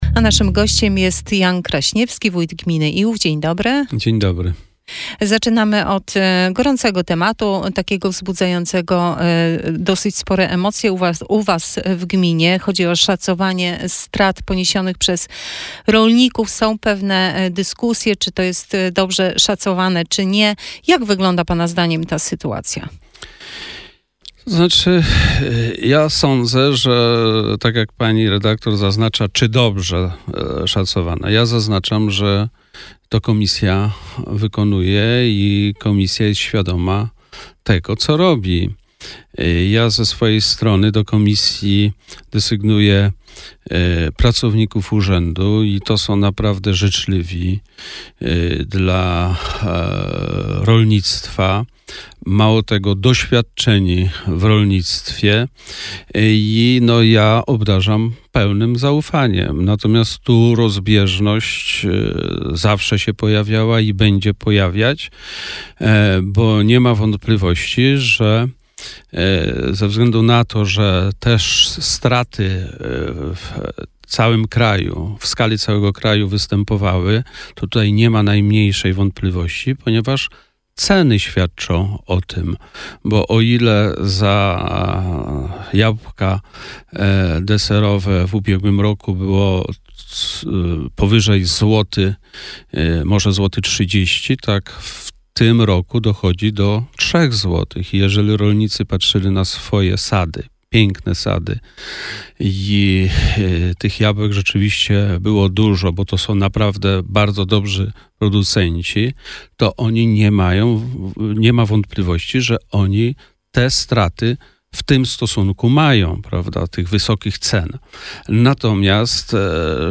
Wywiad z Wójtem Janem Kraśniewskim w Radio Sochaczew - Najnowsze - Gmina Iłów